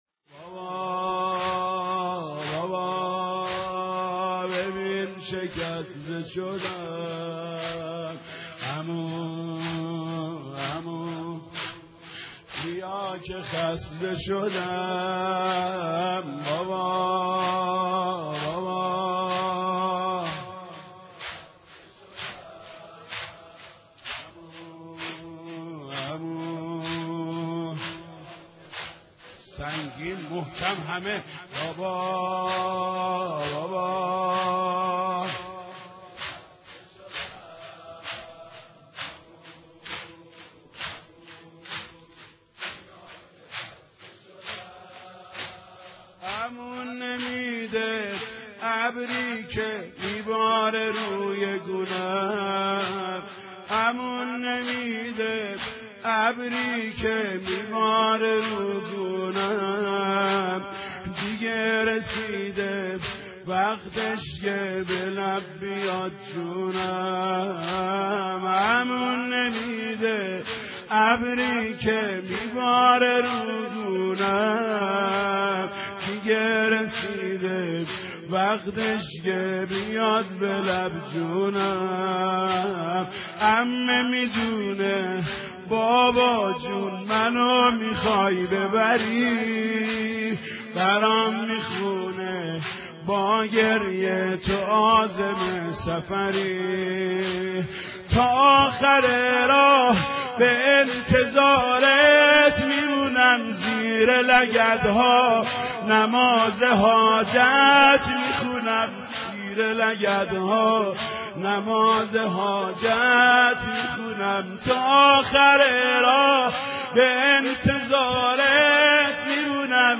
نوحه خوانی